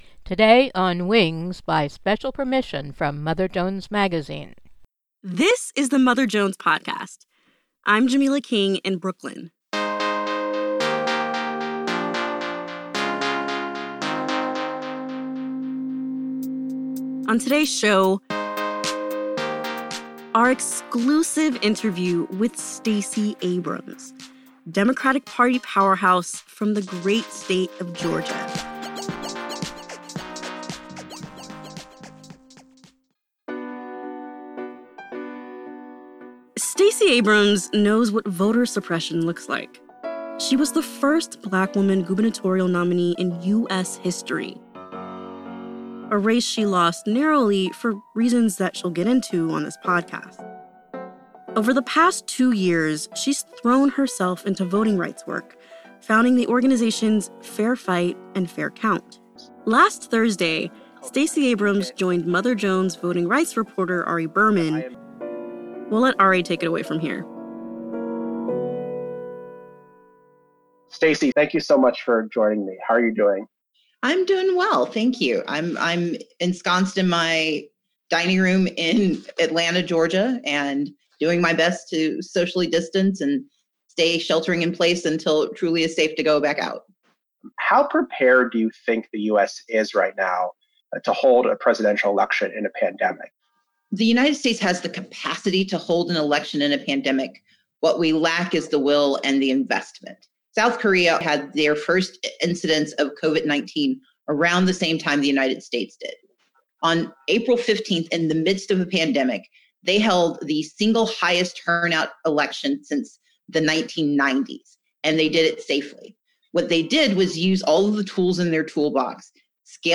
An interview from Mother Jones Magazine